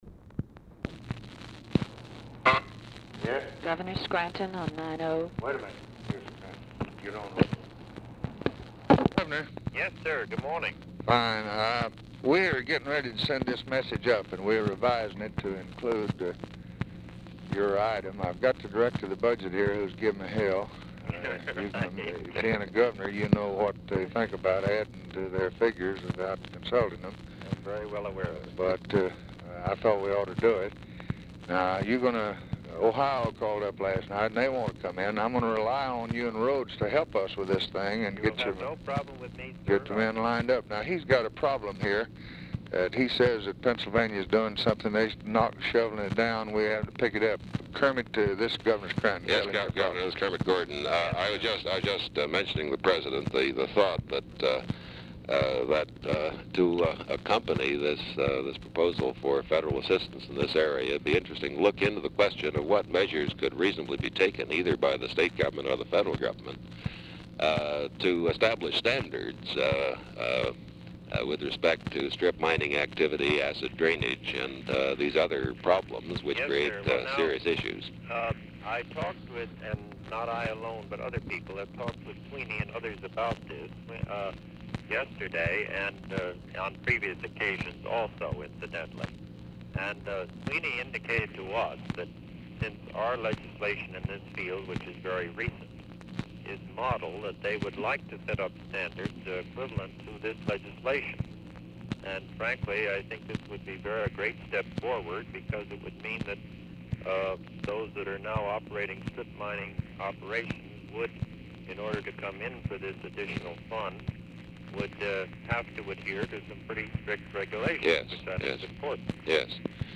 Telephone conversation # 3152, sound recording, LBJ and WILLIAM SCRANTON, 4/28/1964, 12:58PM | Discover LBJ
SCRANTON IS IN HARRISBURG, PENNSYLVANIA
Format Dictation belt
Location Of Speaker 1 Oval Office or unknown location